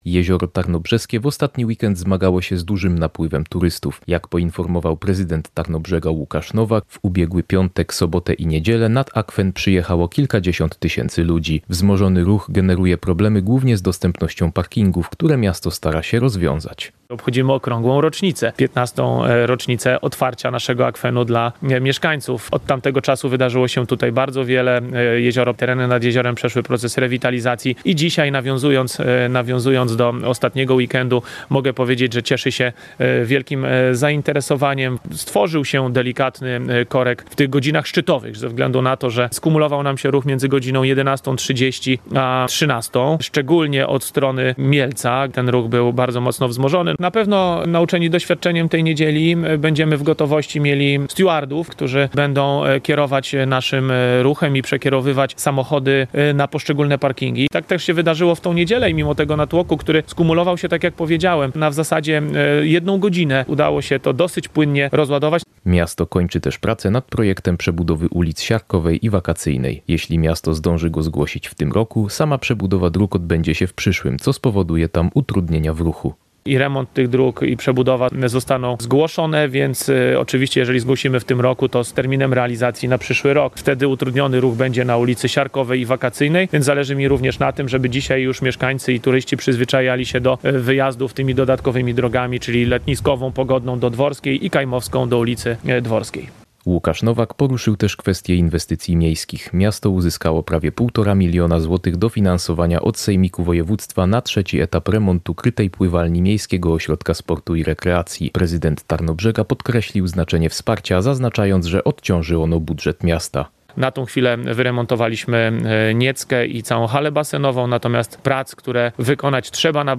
– poinformował prezydent Tarnobrzega Łukasz Nowak.